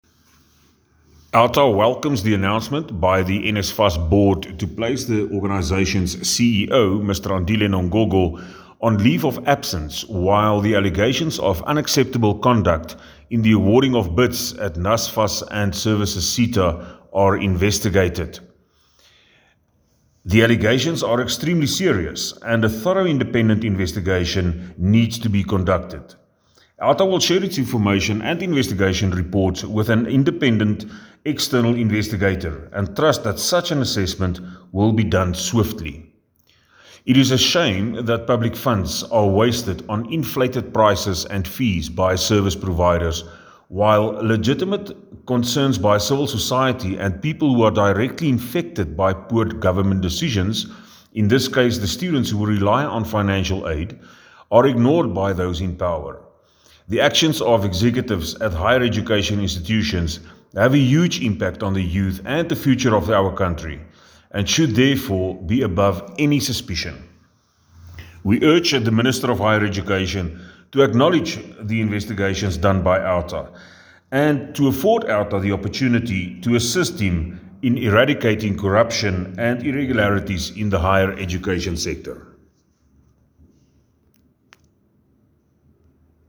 A soundclip with comment